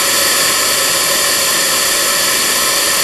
rr3-assets/files/.depot/audio/sfx/forced_induction/turbo_03.wav
turbo_03.wav